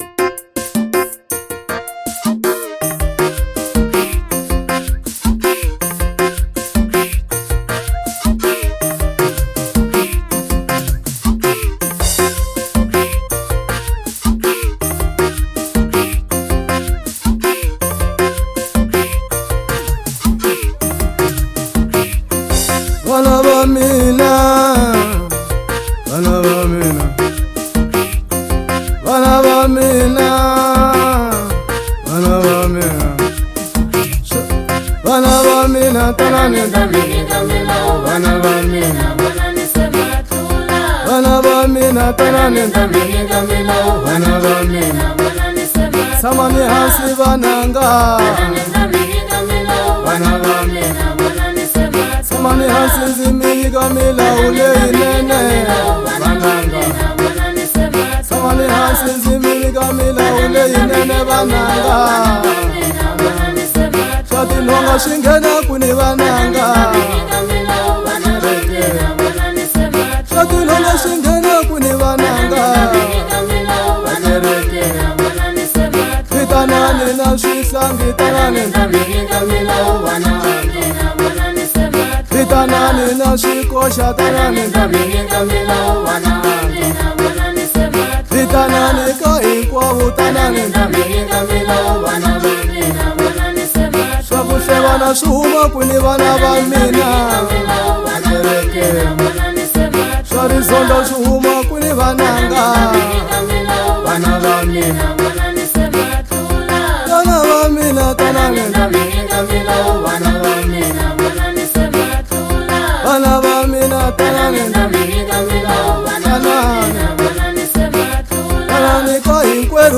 06:25 Genre : Gospel Size